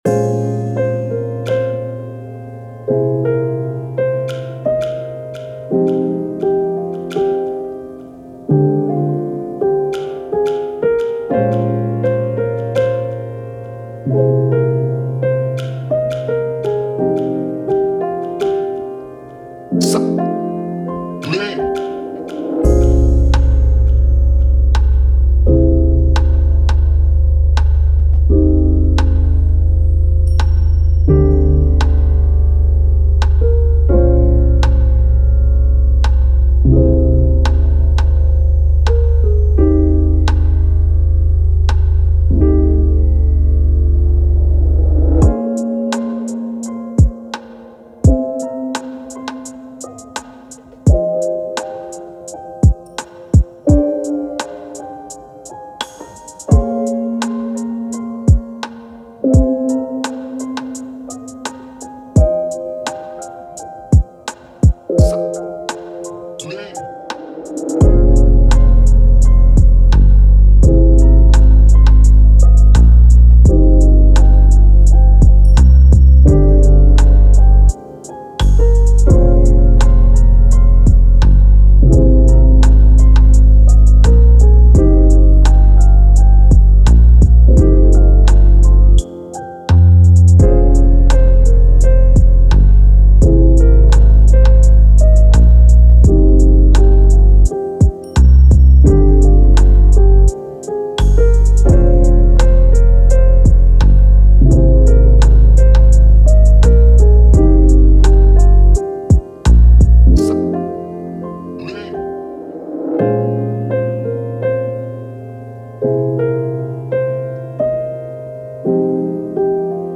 Calm, Chill, Smooth, Vibe
Drum, Piano, Bass